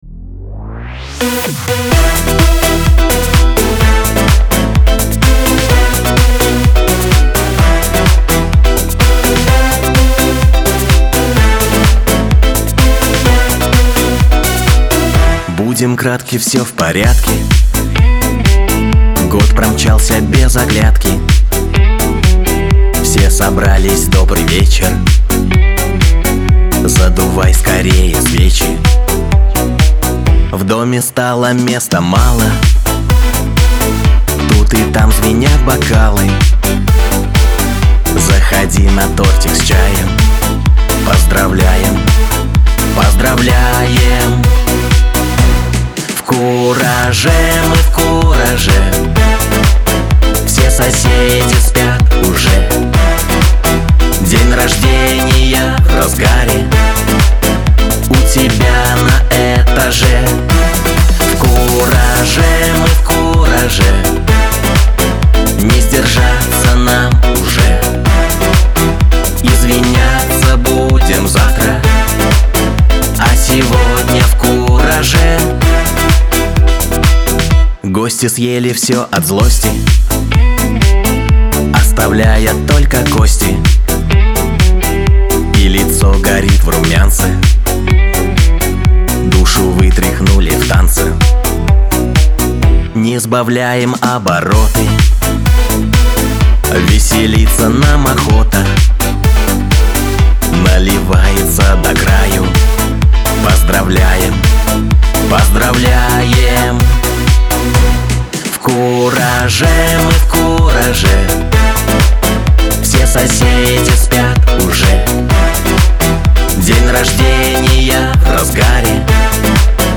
Шансон
Веселая музыка